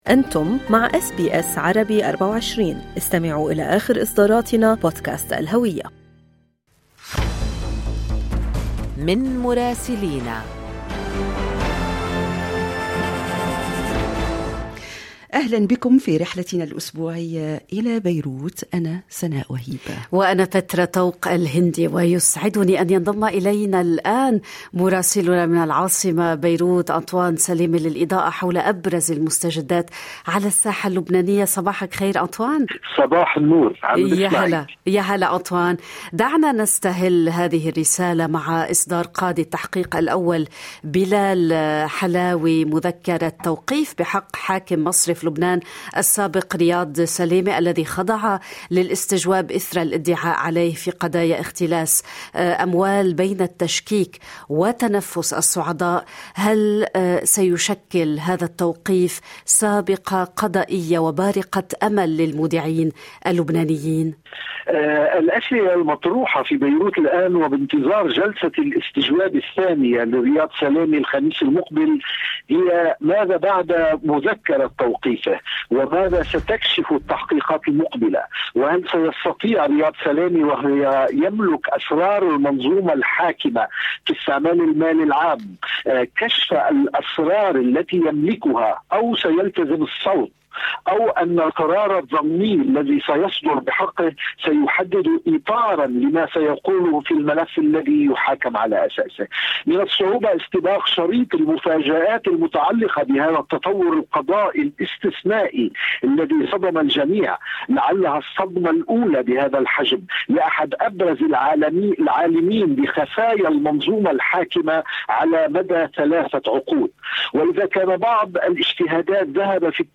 كما ويتطرق الى انتهاء مهرجانات الصيف مع افتتاح العام الدراسي وسط ارتفاع صرخات الأهل تجاه الفاتورة التربوية الباهظة والاستعداد للموسم الثقافي تزامنا مع بدء الموسم الرياضي. يمكنكم الاستماع إلى تقرير مراسلنا في العاصمة بيروت بالضغط على التسجيل الصوتي أعلاه.